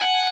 guitar_024.ogg